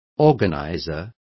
Complete with pronunciation of the translation of organizer.